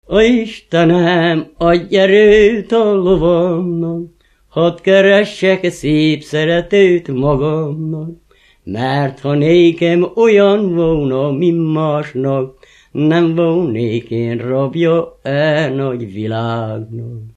Erdély - Udvarhely vm. - Felsősófalva
Műfaj: Lassú csárdás
Stílus: 4. Sirató stílusú dallamok
Kadencia: 5 (4) 5 1